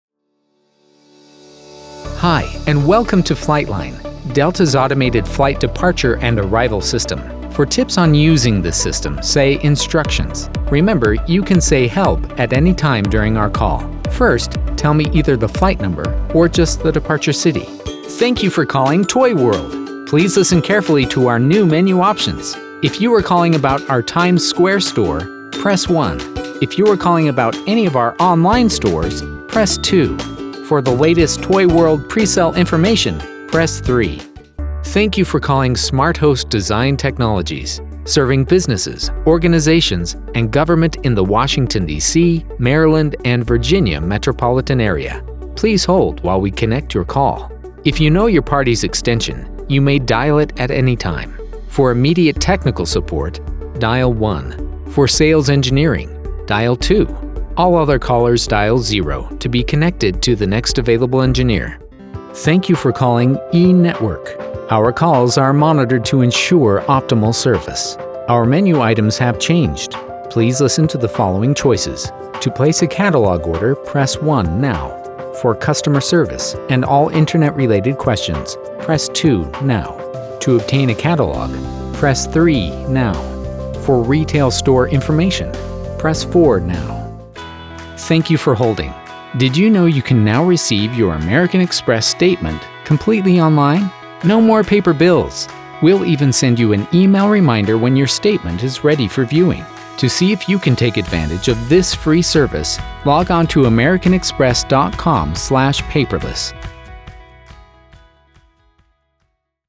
IVR Demo
Confident, Happy, Friendly